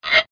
scrape0d.mp3